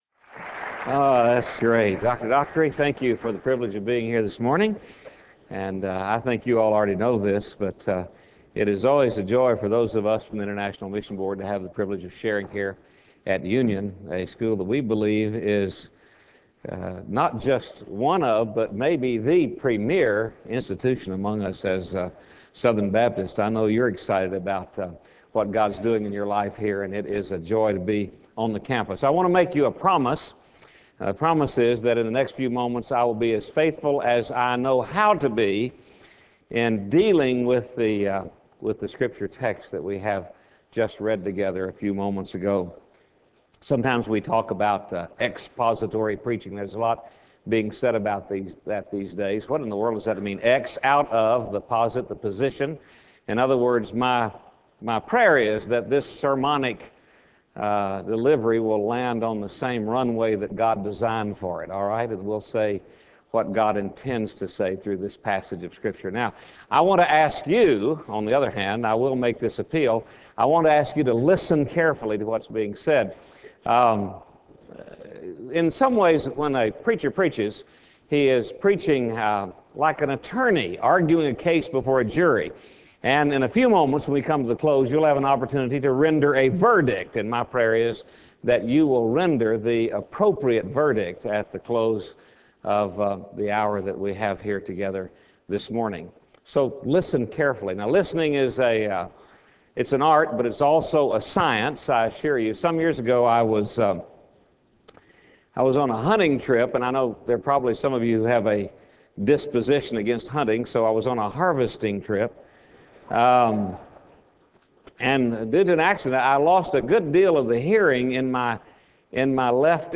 Chapel